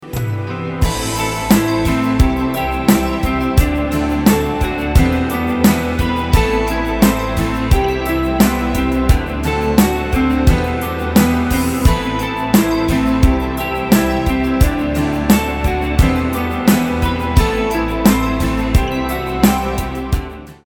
• Качество: 320, Stereo
гитара
спокойные
без слов
инструментальные